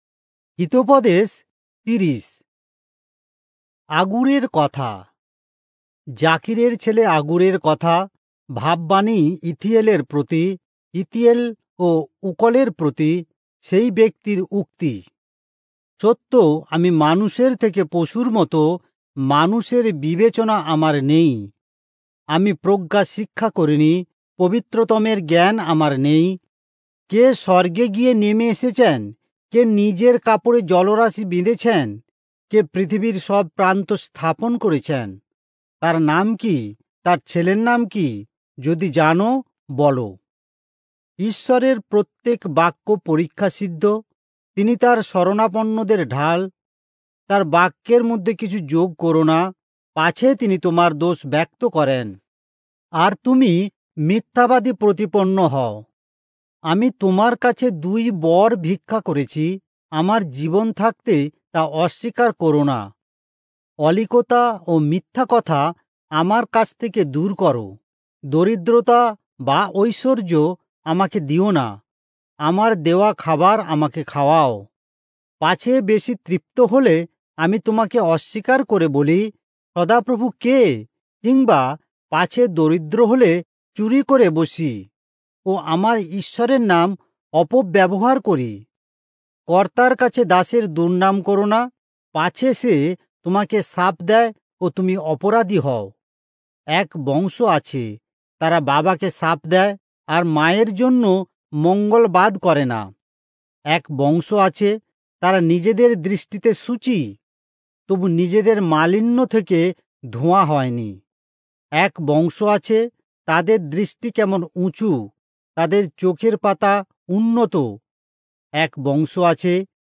Bengali Audio Bible - Proverbs 21 in Irvbn bible version